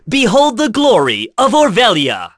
Roman-Vox_Skill7.wav